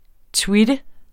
Udtale [ ˈtwidə ]